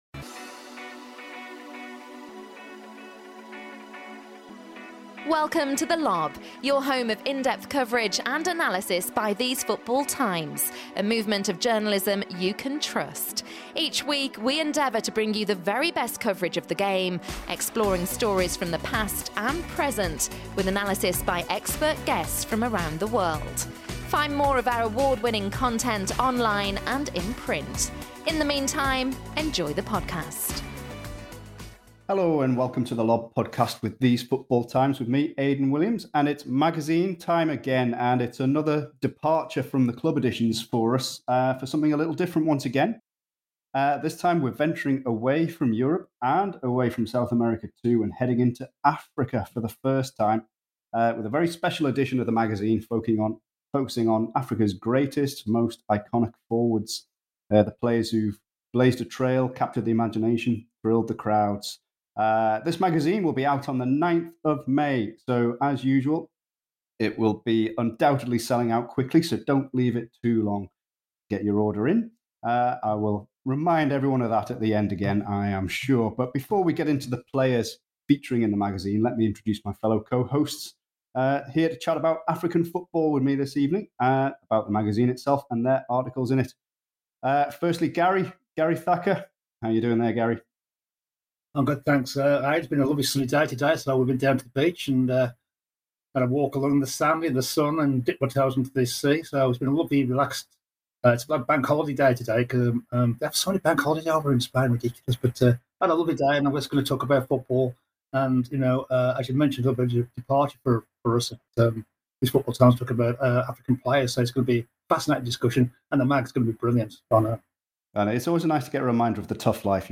To join the launch of the African Forwards magazine, the podcast team got together to discuss our list of 15 legends to emerge from the continent. From undisputed picks like Drogba, Eto'o and Weah to lesser-known legends including Bwalya, Keita and Yekini, the team discuss the merits of each.